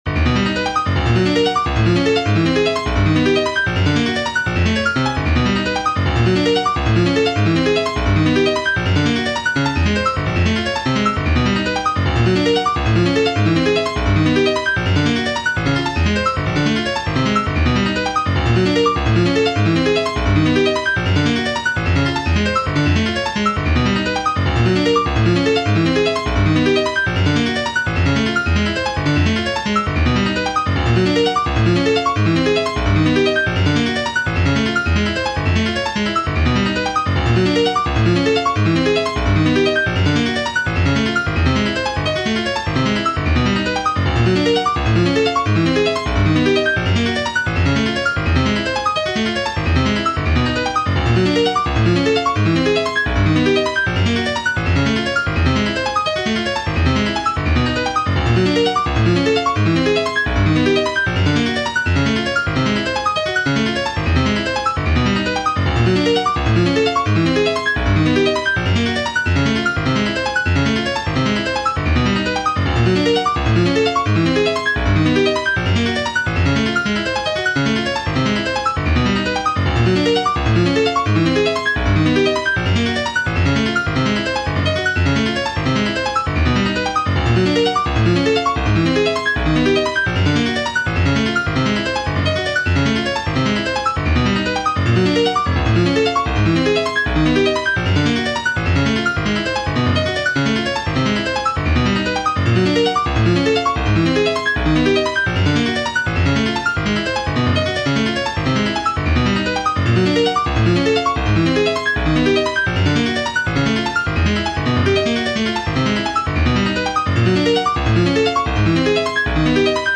There is a bit more variety, but you get long stretches in the middle that are quite repetitive: